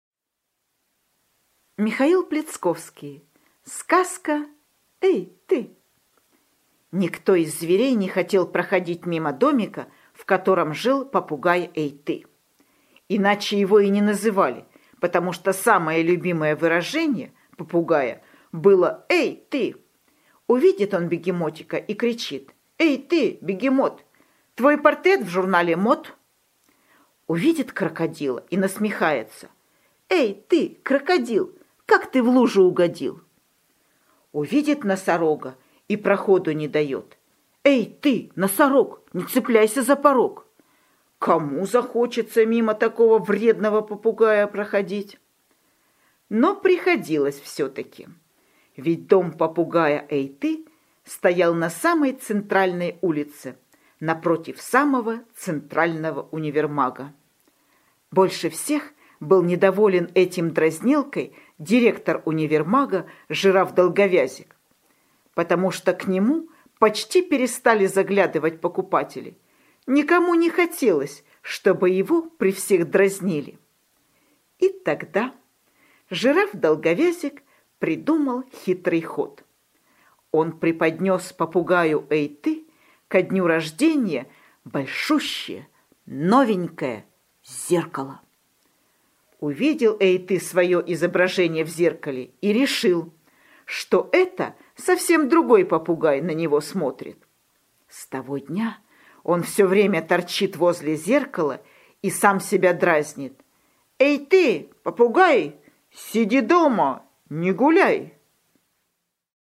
Аудиосказка «Эй, ты!»